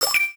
From The Cutting Room Floor Jump to navigation Jump to search File File history File usage Metadata DTL-SFX_TOKEN_GET.ogg  (Ogg Vorbis sound file, length 0.4 s, 520 kbps) This file is an audio rip from a(n) Nintendo DS game.
DTL-SFX_TOKEN_GET.ogg